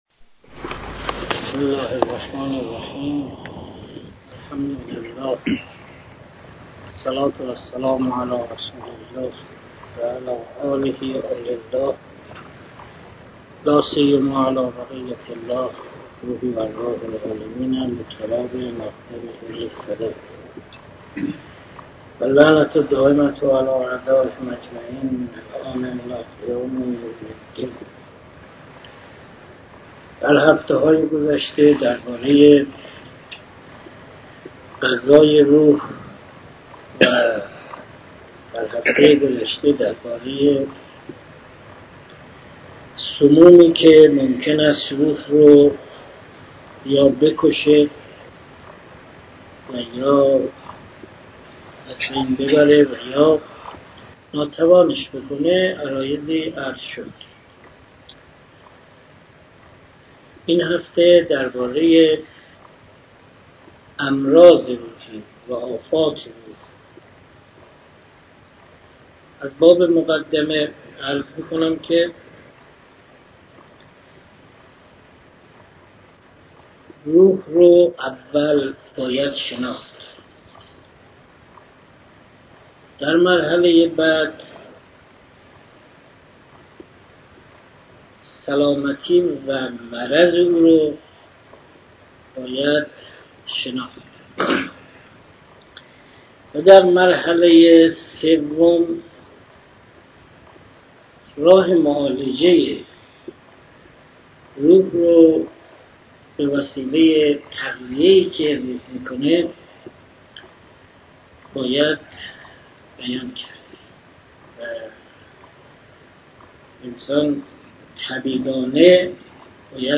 درس خصوصی غذای روح: جلسه دهم